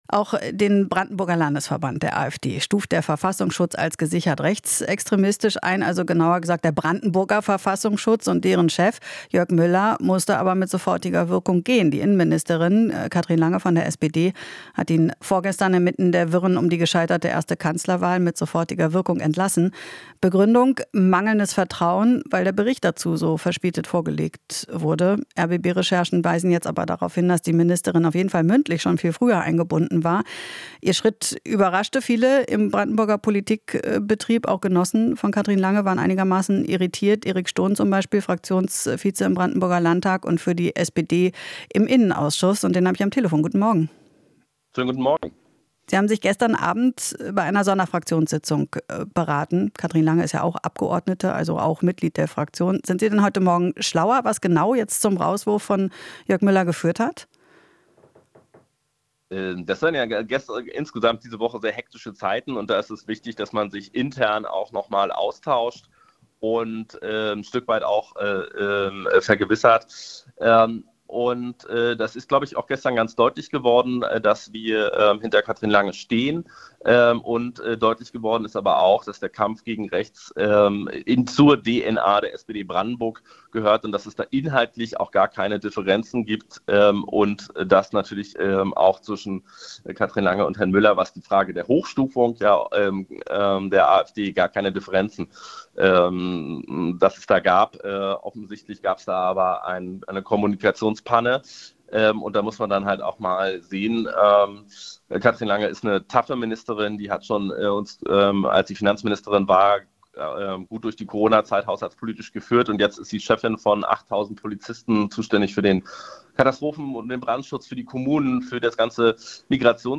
Interview - Brandenburger SPD-Fraktion steht hinter Innenministerin Lange